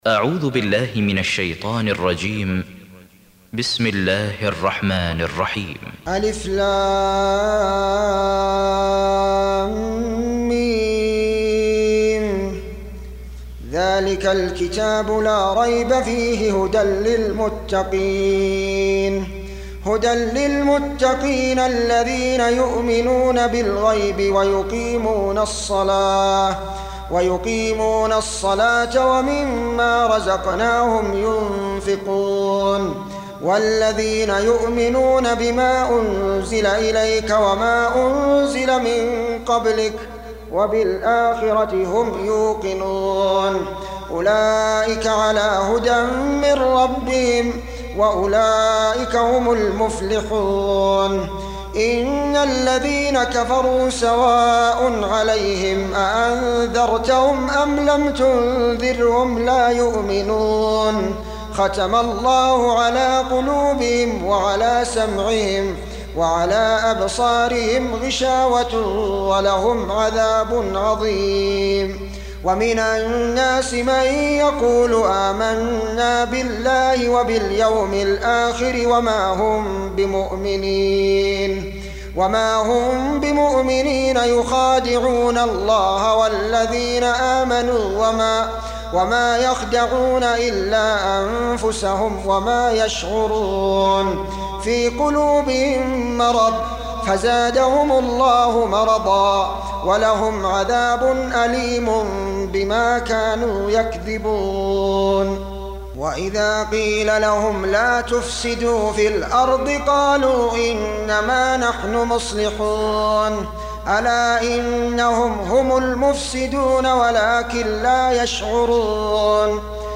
2. Surah Al-Baqarah سورة البقرة Audio Quran Tarteel Recitation
Surah Repeating تكرار السورة Download Surah حمّل السورة Reciting Murattalah Audio for 2. Surah Al-Baqarah سورة البقرة N.B *Surah Includes Al-Basmalah Reciters Sequents تتابع التلاوات Reciters Repeats تكرار التلاوات